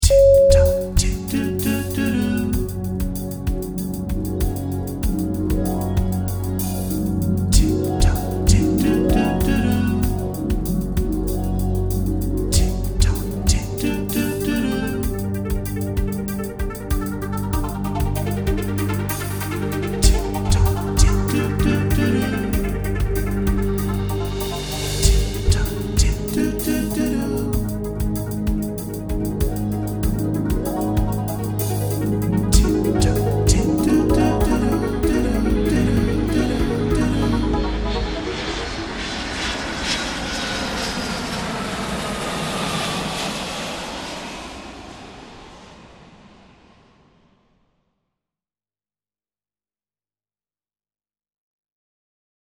Pre-show (included with optional sound design package)